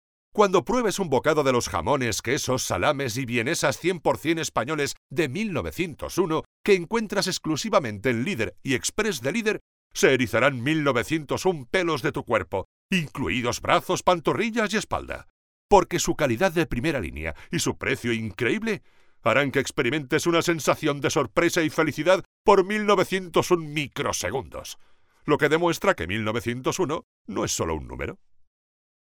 cuña de radio realizada para  emisora de radio de Chile. locutores de anuncios, anuncios radio